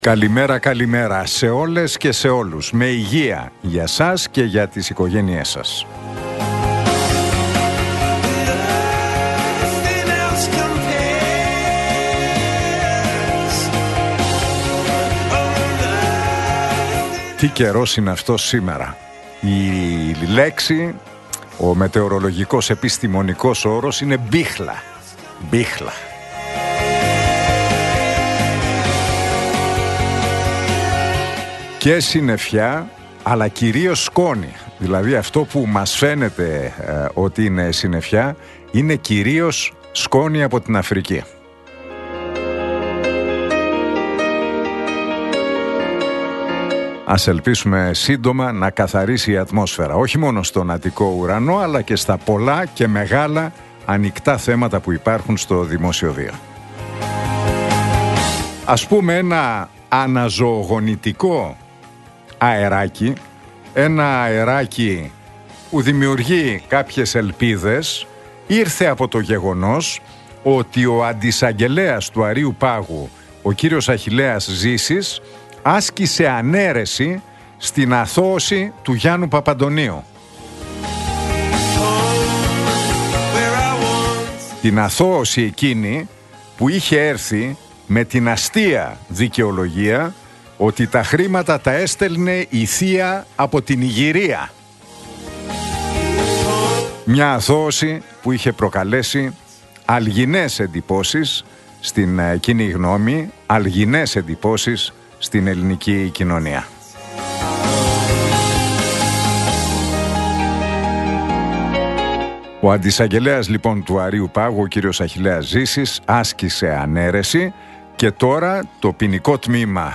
Ακούστε το σχόλιο του Νίκου Χατζηνικολάου στον ραδιοφωνικό σταθμό Realfm 97,8, την Τετάρτη 7 Μαΐου 2025.